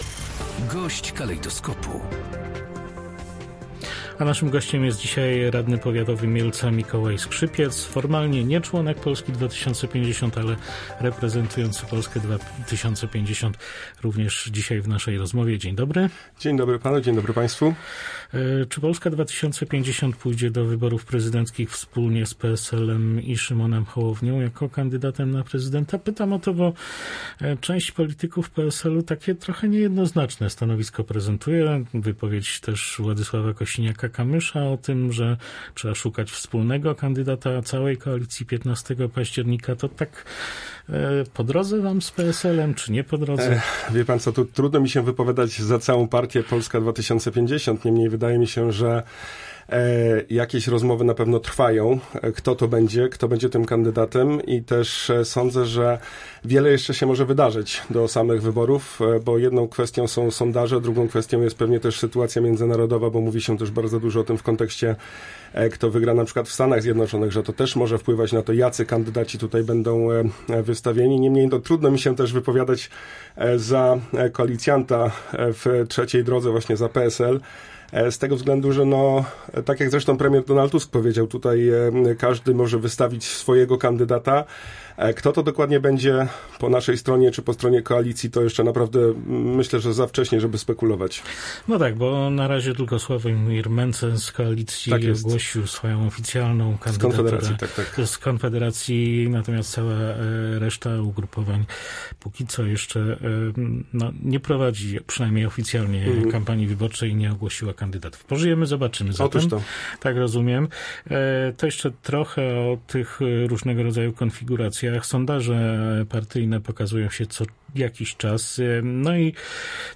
Projekt Centralnego Portu Komunikacyjnego (CPK) jest kontynuowany i będzie realizowany – powiedział na antenie Radia Rzeszów Mikołaj Skrzypiec, radny powiatu mieleckiego z Polski 2050.